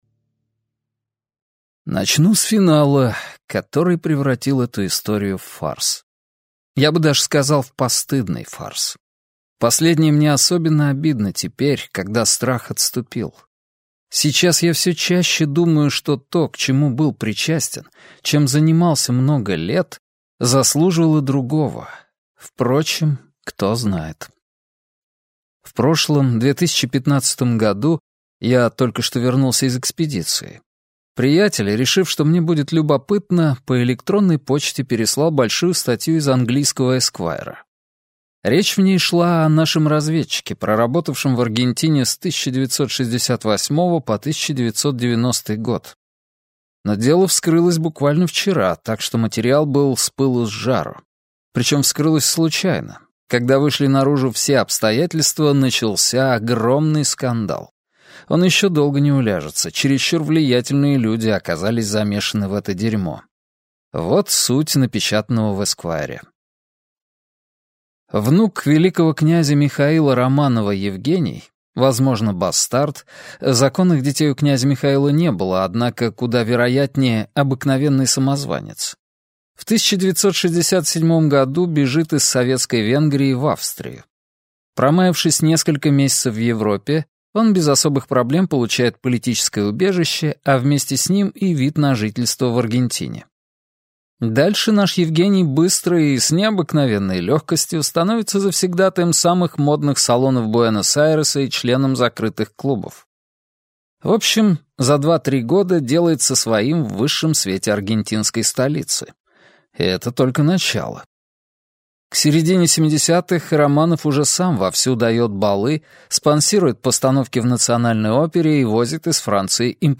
Аудиокнига Царство Агамемнона | Библиотека аудиокниг